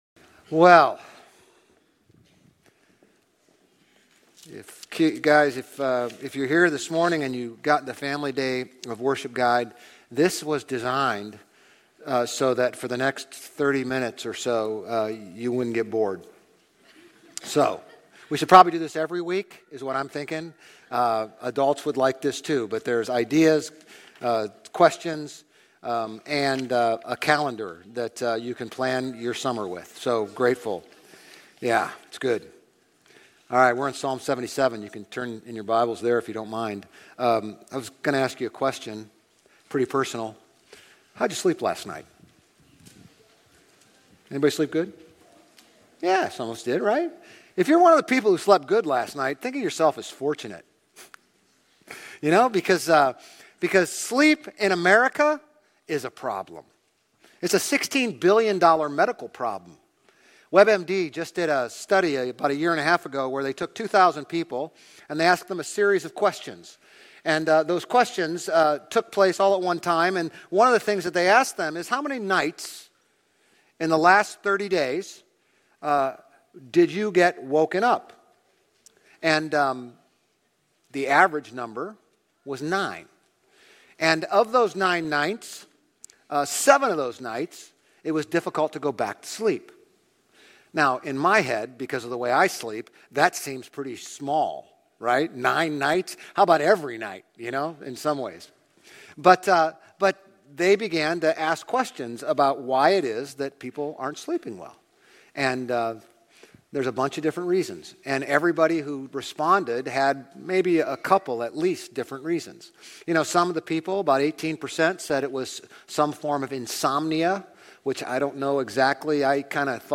Grace Community Church Old Jacksonville Campus Sermons Psalm 77 - Lament Jul 01 2024 | 00:32:09 1x 00:00 / 00:32:09 Subscribe Share RSS Feed Share Link Embed